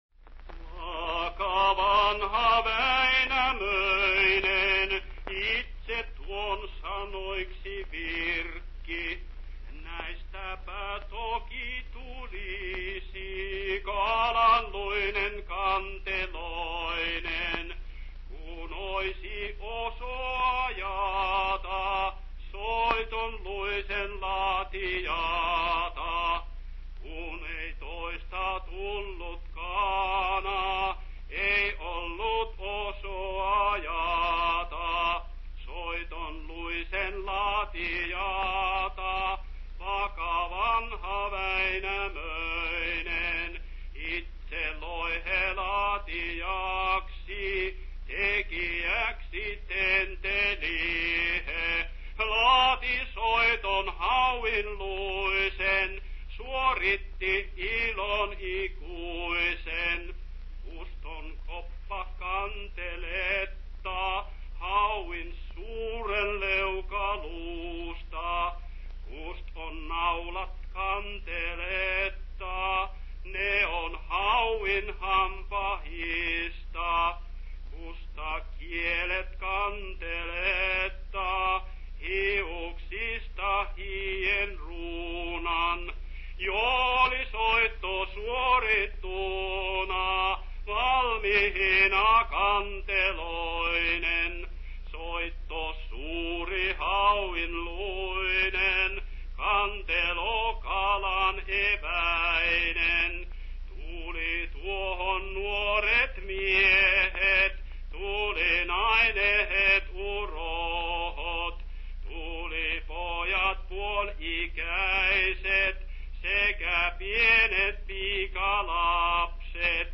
sung excerpt, excerpt lyrics).